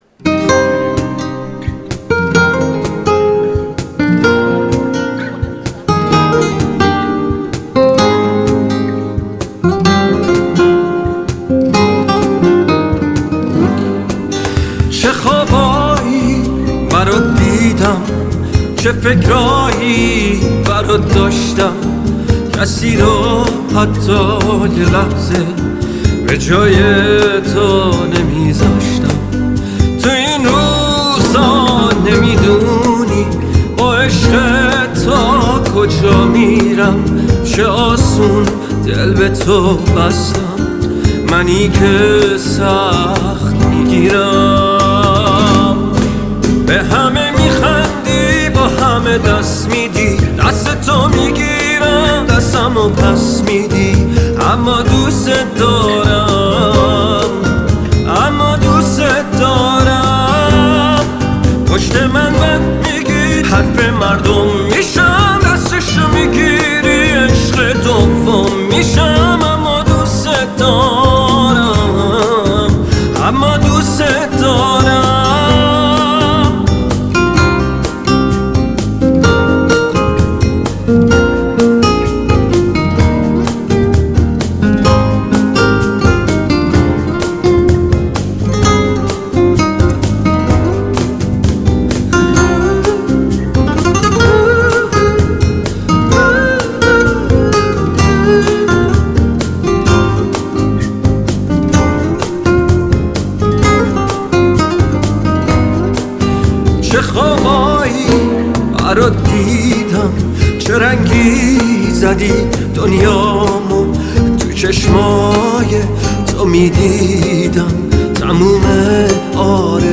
اجرای آکوستیک زنده آنپلاگد